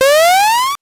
powerup_24.wav